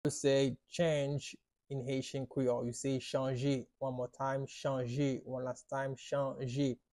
“Change” in Haitian Creole – “Chanjman” pronunciation by a Haitian tutor
“Chanjman” Pronunciation in Haitian Creole by a native Haitian can be heard in the audio here or in the video below:
How-to-say-Change-in-Haitian-Creole-–-Chanjman-pronunciation-by-a-Haitian-tutor.mp3